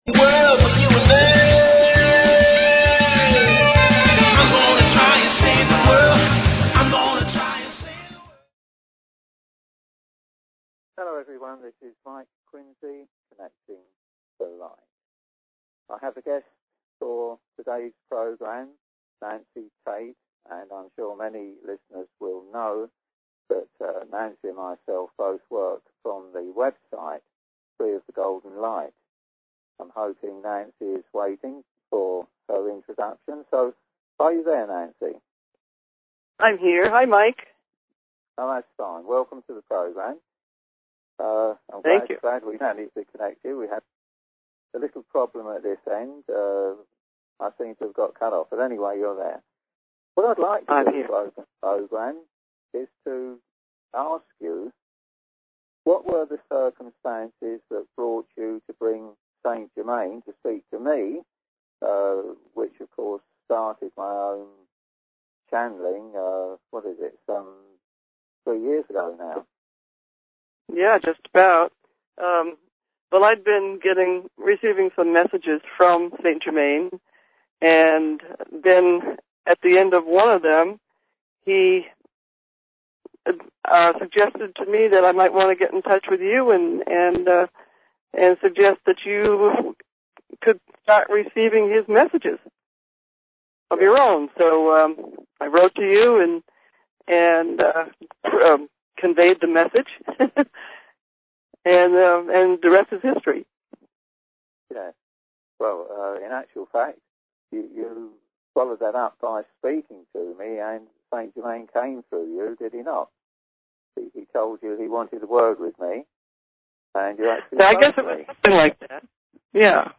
Talk Show Episode, Audio Podcast, Connecting_The_Light and Courtesy of BBS Radio on , show guests , about , categorized as
Interview